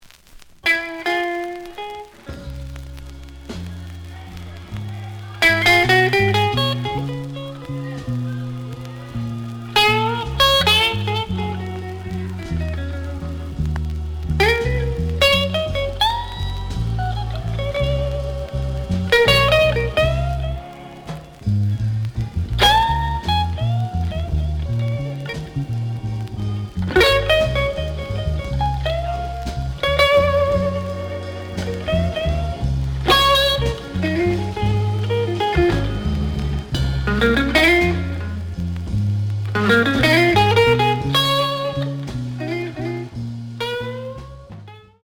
The audio sample is recorded from the actual item.
●Genre: Blues
Edge warp.